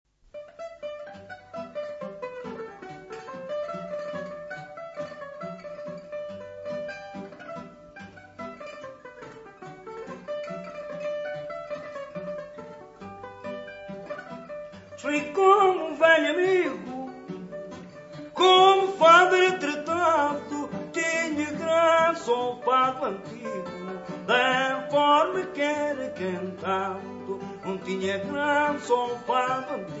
Fado corrido